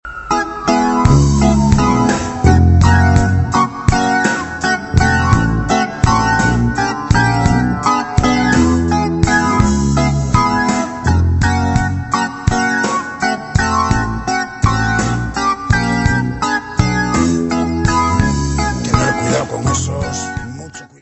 : stereo; 12 cm